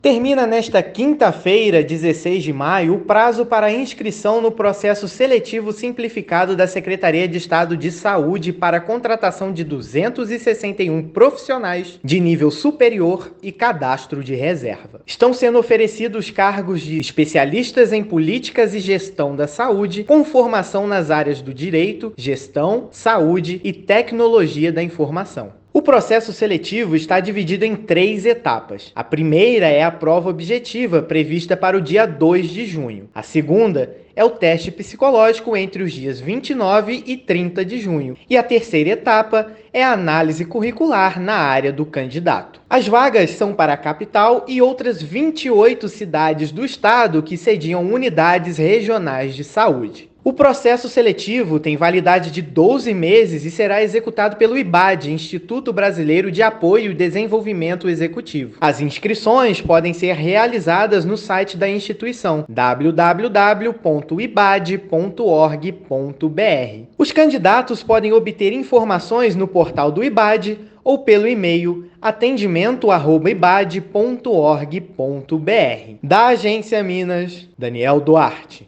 Secretaria de Saúde tem inscrições abertas até quinta (16/5) para contratar 261 profissionais de nível superior. Ouça matéria de rádio.